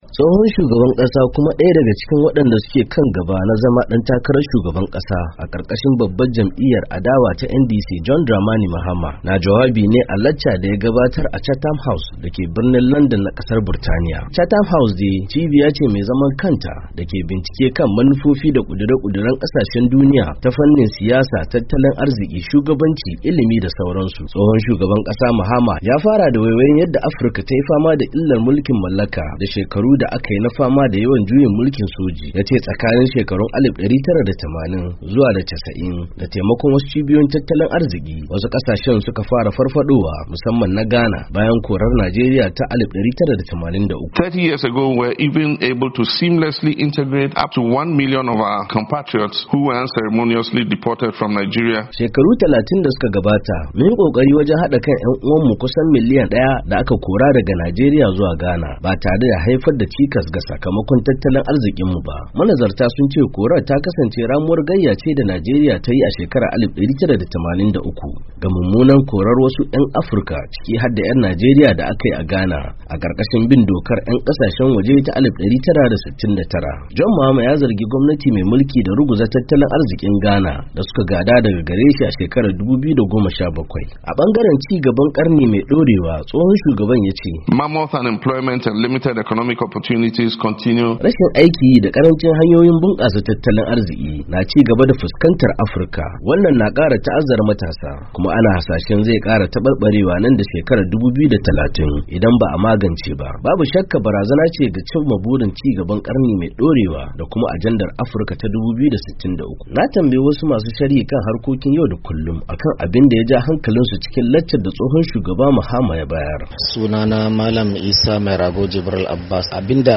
Tsohon Shugaban Ghana John Mahama Ya Gabatar Da Jawabi A Cibiyar ‘Chatham House Da Ke Landan